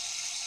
spray.ogg